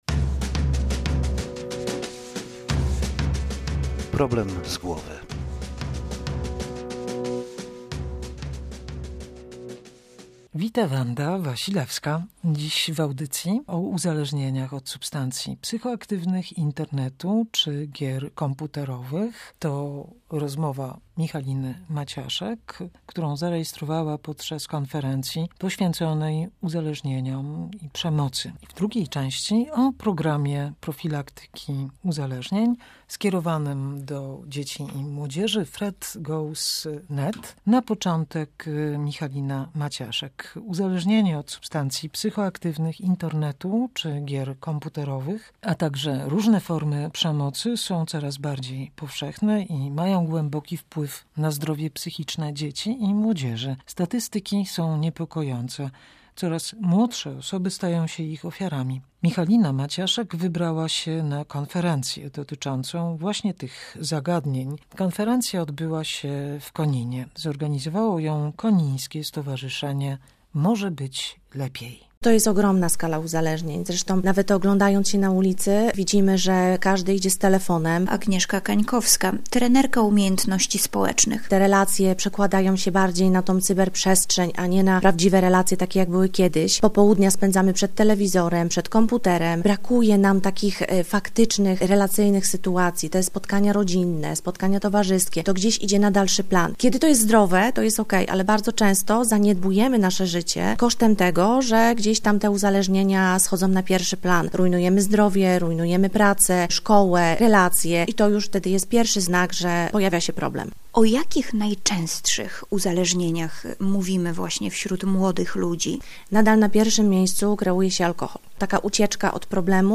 Problem z głowy poświęcony profilaktyce uzależnień. W pierwszej części rozmowa